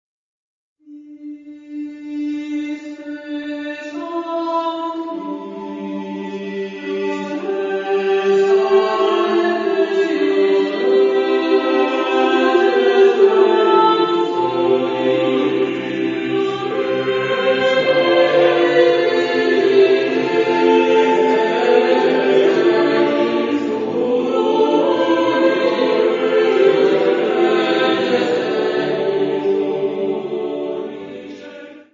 Epoque : 16 ; 17ème s.
Genre-Style-Forme : Motet ; Sacré
Type de choeur : SATB  (4 voix mixtes )
Tonalité : mode de sol